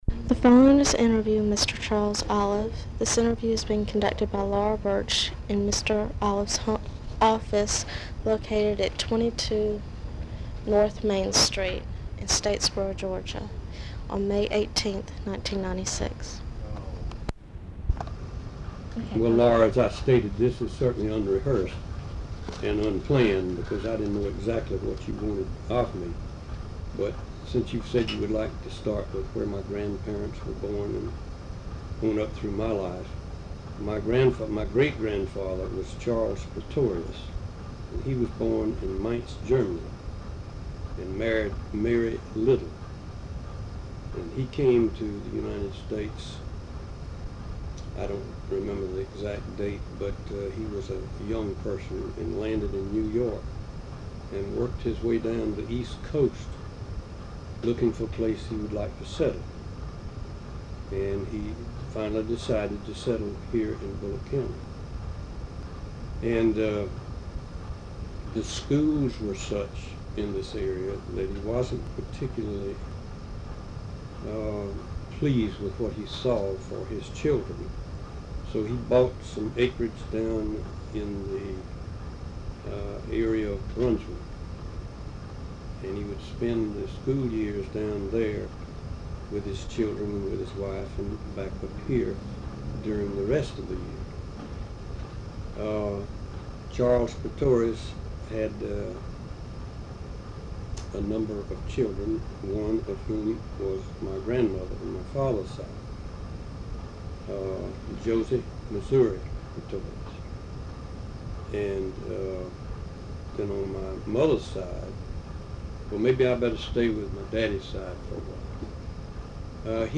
Audio file digitized from cassette tape.